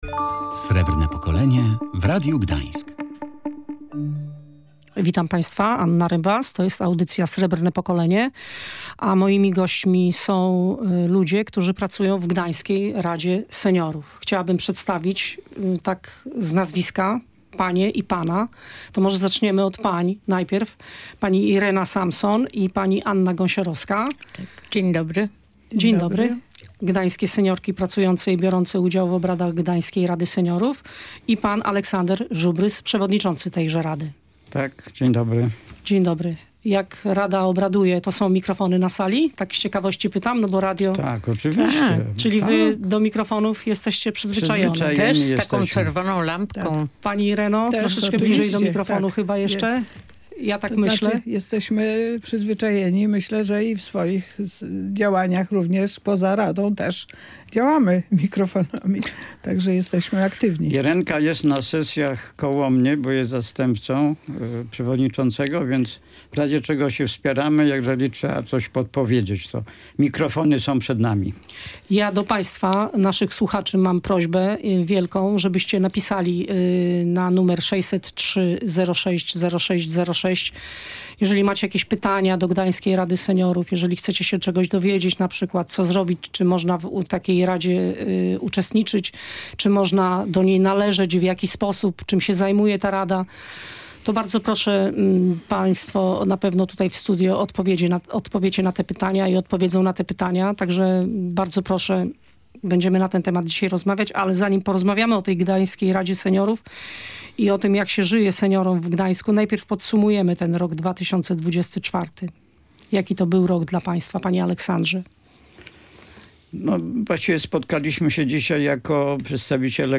Przedstawiciele Gdańskiej Rady Seniorów w audycji „Srebrne Pokolenie”